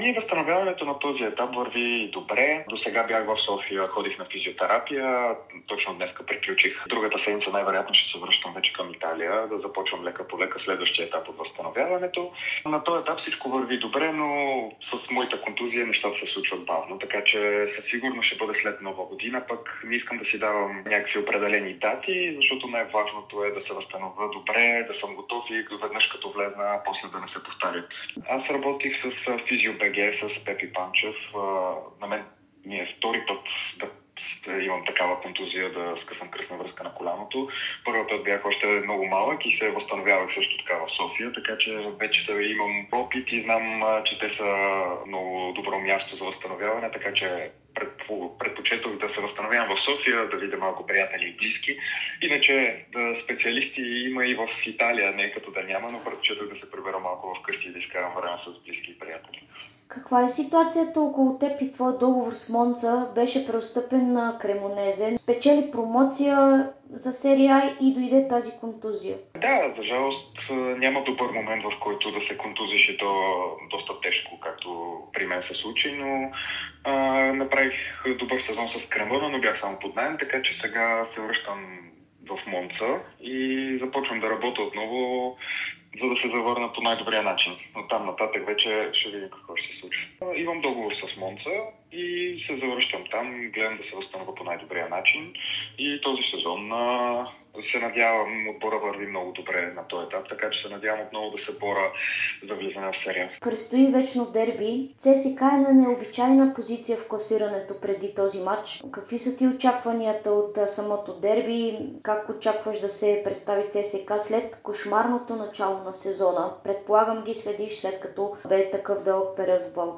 Бившият капитан на ЦСКА - Валентин Антов, говори ексклузивно пред Дарик радио и dsport. Бранителят сподели как върви възстановяването му от тежка контузия в коляното и коментира предстоящото дерби между ЦСКА и Левски.